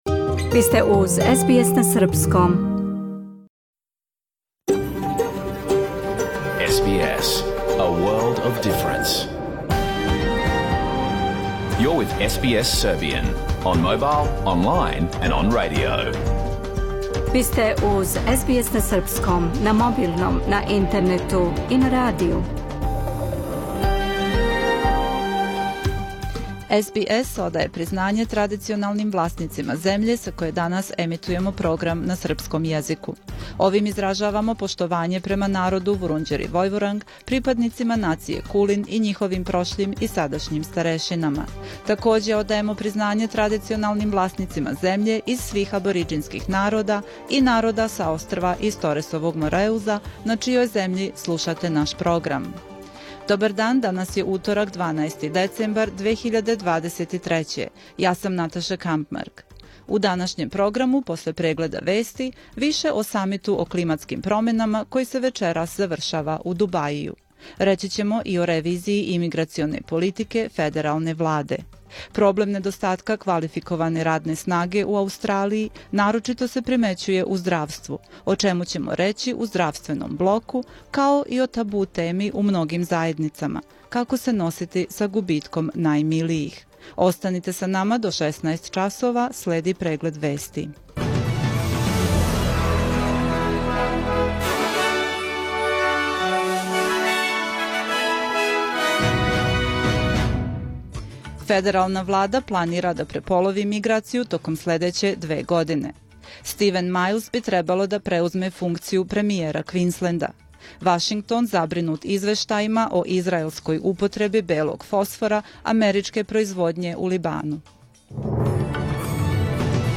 Програм емитован уживо 12. децембра 2023. године
Уколико сте пропустили данашњу емисију, можете је послушати у целини као подкаст, без реклама.